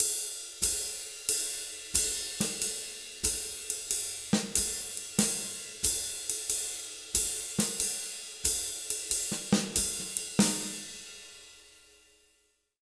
92SWING 05-R.wav